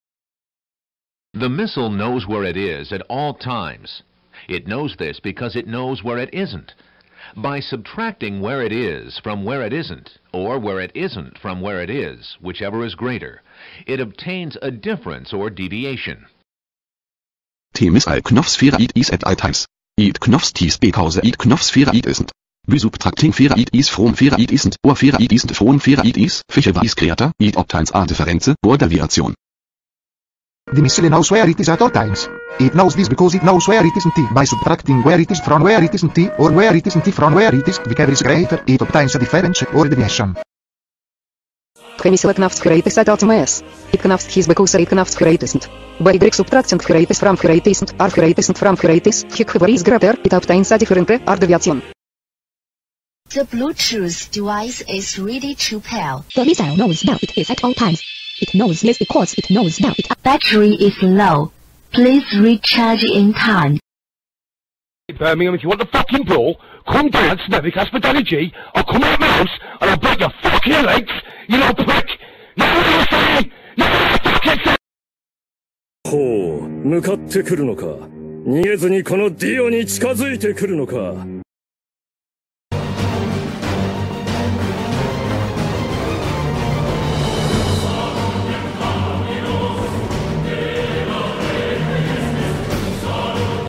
Gotta have white noise to sound effects free download